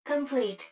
153_Complete.wav